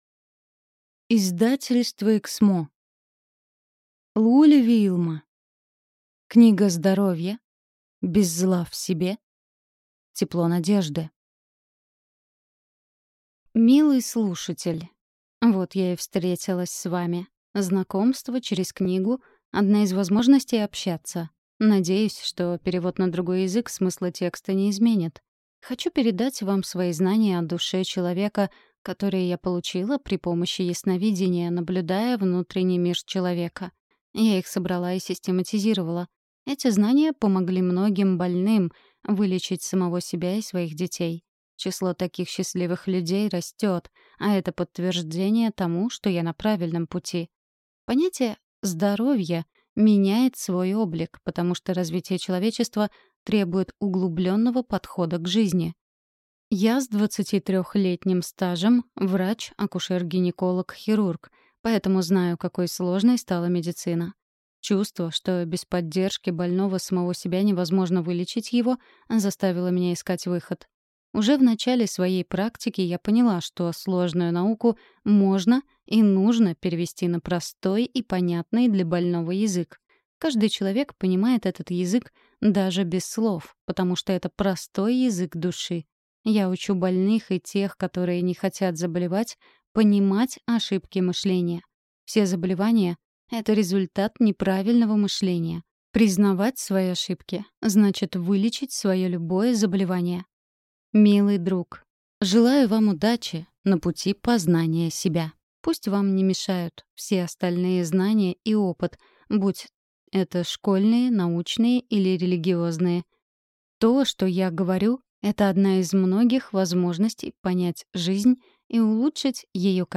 Аудиокнига Книга здоровья. Без зла в себе. Тепло надежды | Библиотека аудиокниг